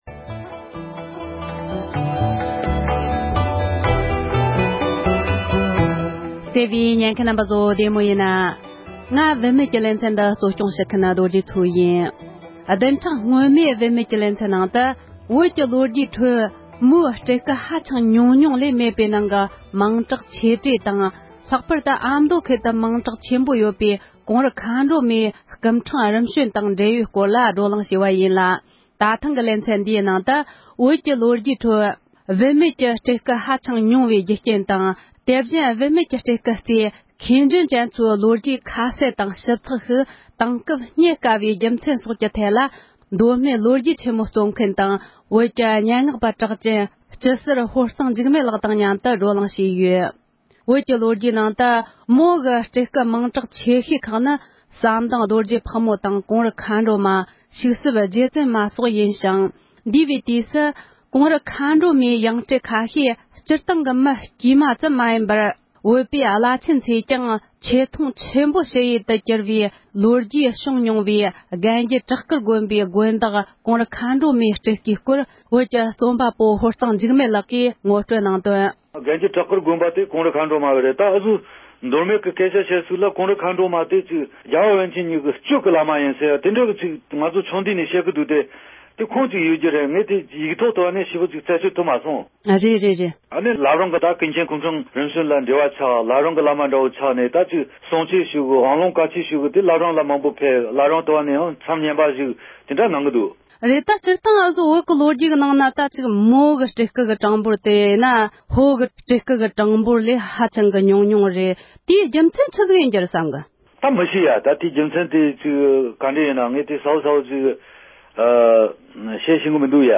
བོད་ཀྱི་ལོ་རྒྱུས་ཁྲོད་བུད་མེད་ཀྱི་སྤྲུལ་སྐུའི་གྲངས་འབོར་དེ་སྐྱེས་པ་ལས་ཧ་ཅང་ཉུང་བའི་རྒྱུ་རྐྱེན་སྐོར་གླེང་མོལ།